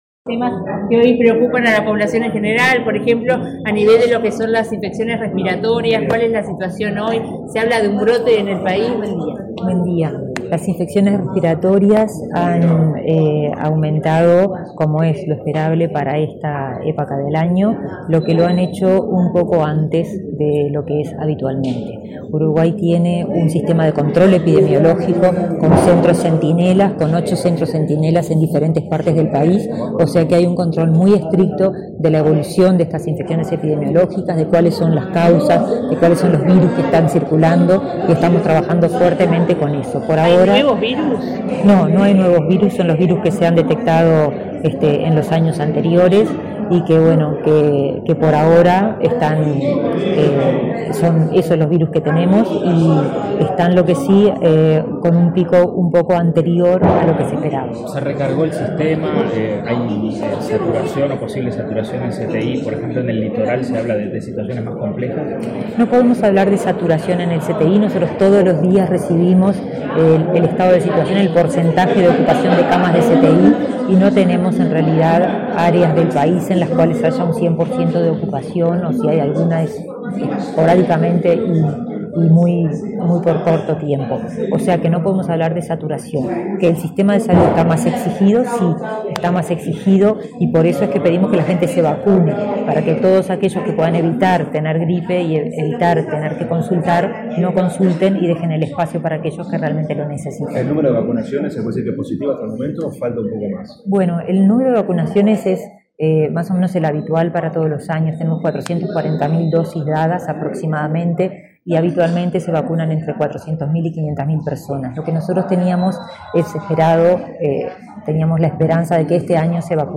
Declaraciones a la prensa de la ministra de Salud Pública, Karina Rando
Declaraciones a la prensa de la ministra de Salud Pública, Karina Rando 12/06/2024 Compartir Facebook X Copiar enlace WhatsApp LinkedIn Tras disertar en el almuerzo de trabajo de la Asociación de Marketing del Uruguay (ADM), este 12 de junio, la ministra de Salud Pública, Karina Rando, realizó declaraciones a la prensa.